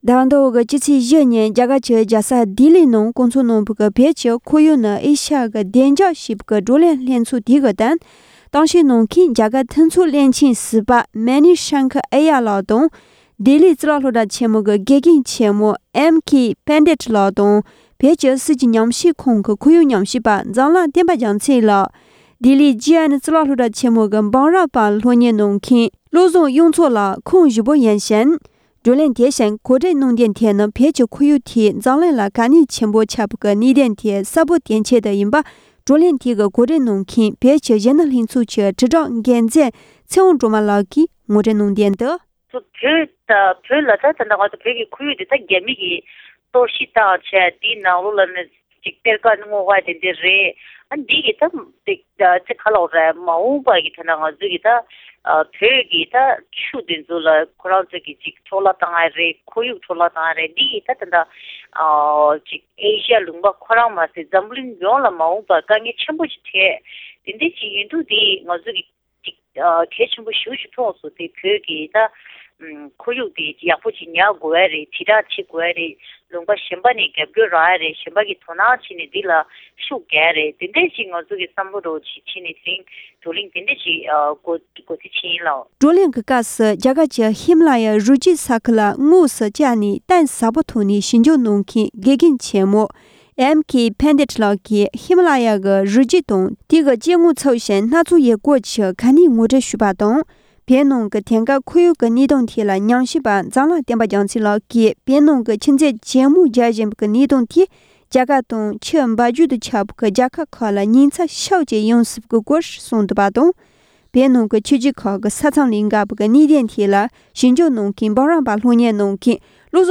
༧གོང་ས་མཆོག་གིས་གཞོན་སྐྱེས་ཚོར་ནང་ཆོས་ངོ་སྤྲོད་གནང་བཞིན་འདུག སྒྲ་ལྡན་གསར་འགྱུར།